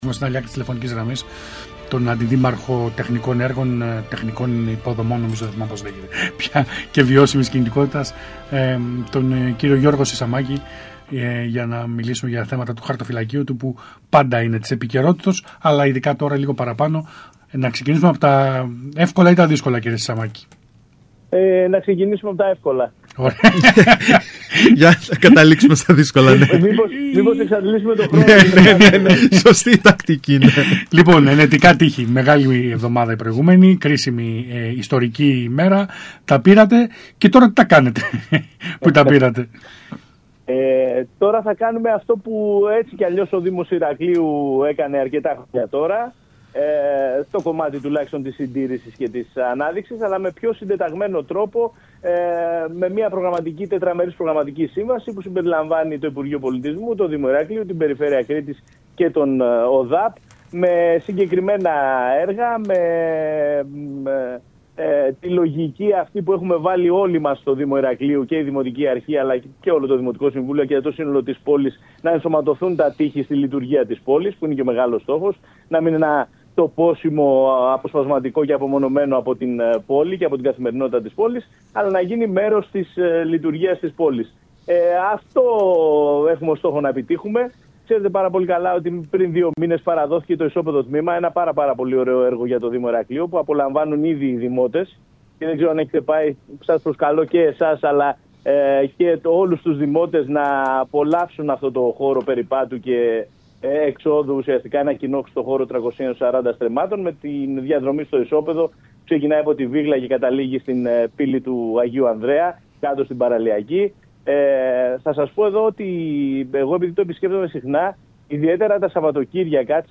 Για το συγκεκριμένο θέμα αλλά και για υπόλοιπα τρέχοντα ζητήματα του Δήμου Ηρακλείου, όπως την αξιοποίηση των Ενετικών Τειχών και τις εργασίες στην Ταξιάρχου Μαρκοπούλου, μίλησε στον ΣΚΑΙ Κρήτης 92.1 ο αρμόδιος Αντιδήμαρχος Γιώργος Σισαμάκης.